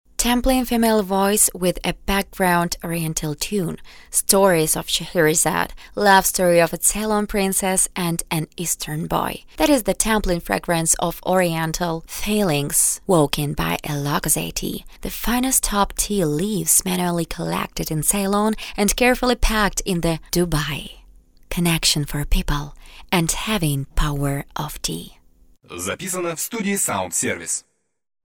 Женщина
Молодой
быстрый
Приглушенный
Средний